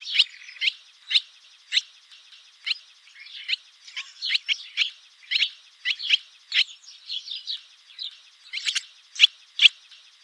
Hirundo rustica - Swallow - Rondine